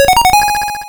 RedCoin2.wav